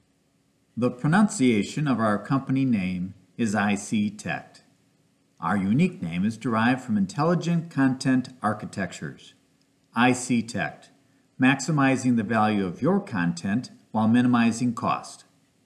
I•c•tect (ī-SĒ-tekt):